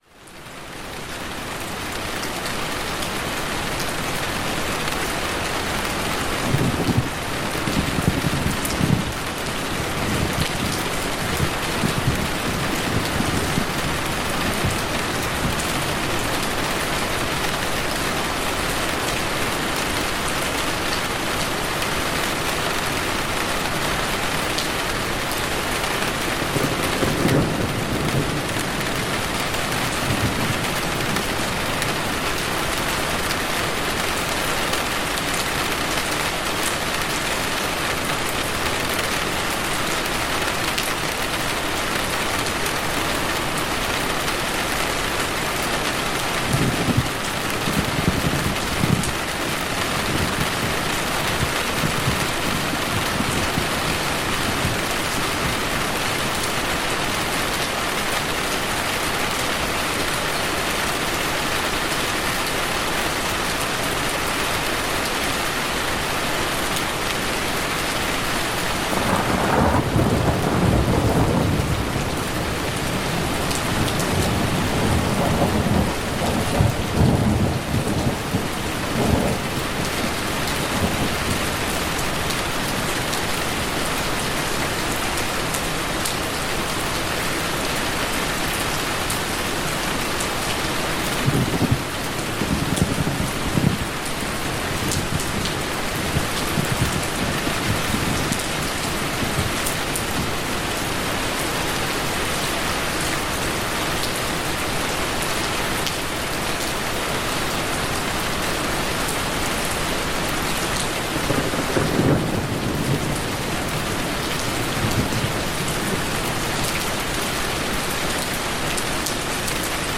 Lluvia y Fuego de Campamento para Calmar la Mente y Relajar el Cuerpo